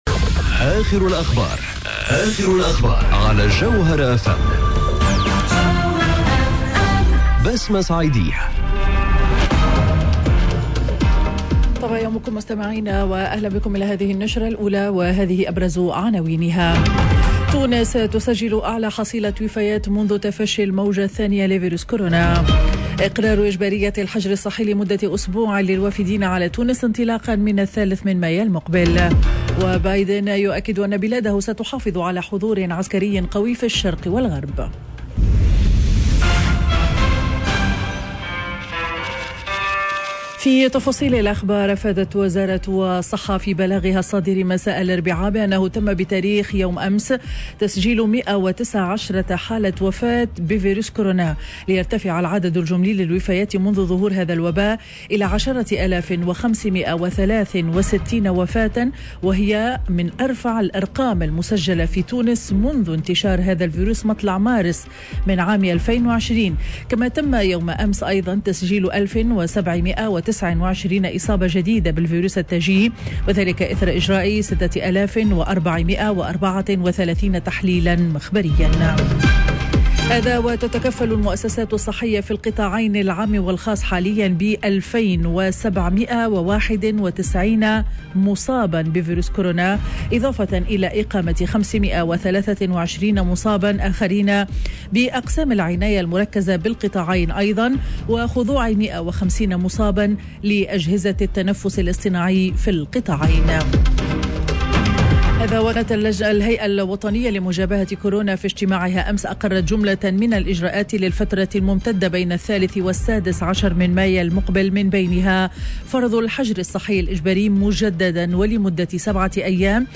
نشرة أخبار السابعة صباحا ليوم الخميس 29 أفريل 2021